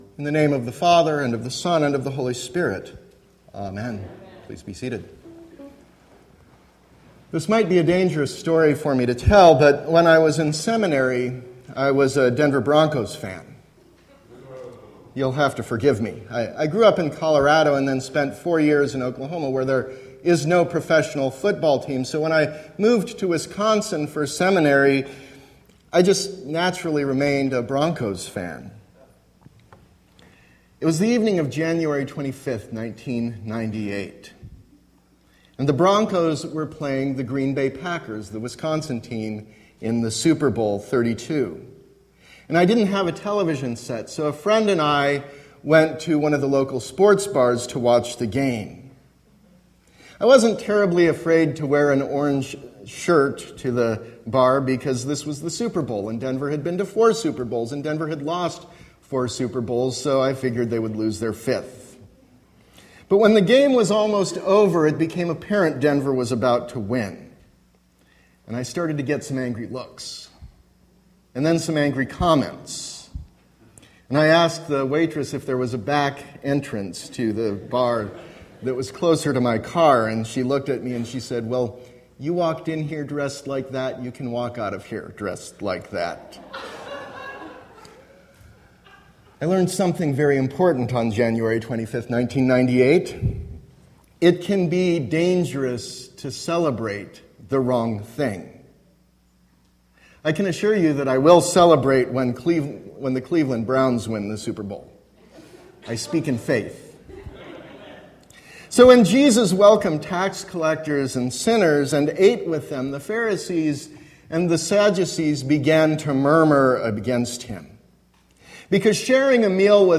Sermon – September 11, 2016